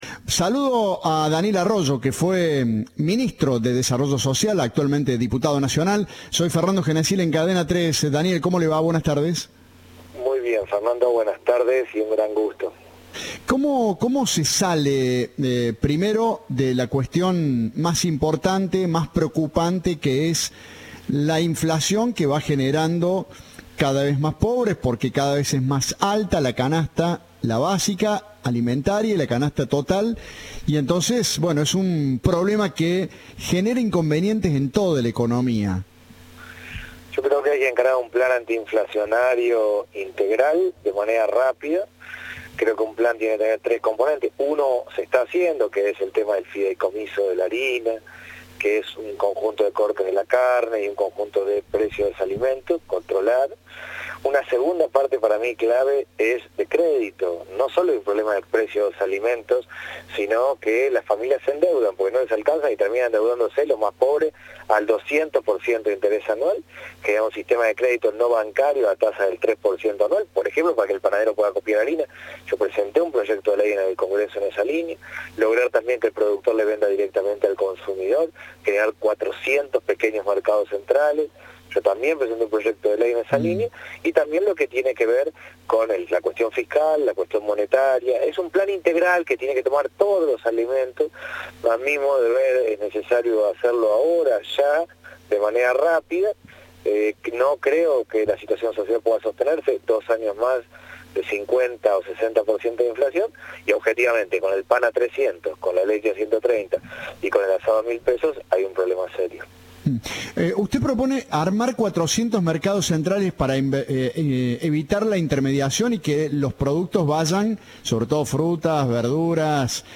Daniel Arroyo, actual diputado nacional por el Frente de Todos y ex ministro de Desarrollo Social de la Nación, conversó con Cadena 3 respecto a la situación económica actual y al desmedido aumento de precios en el país.
Entrevista